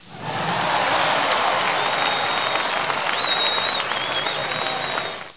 1 channel
00640_Sound_cheers.mp3